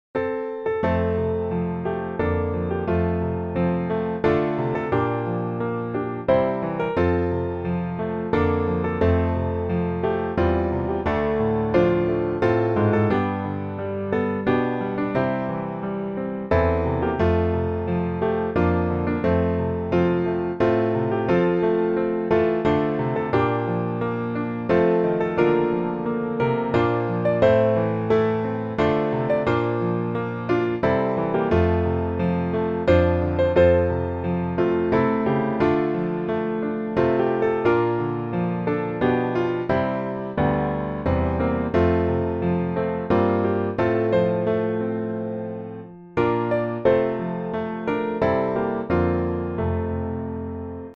F大调